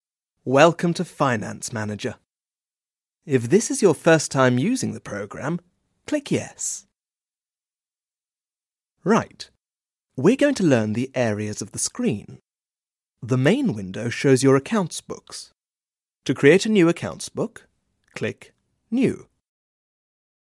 Native British, radio, video game experience
englisch (uk)
Sprechprobe: eLearning (Muttersprache):
I am a British actor, RP, experienced in voice over.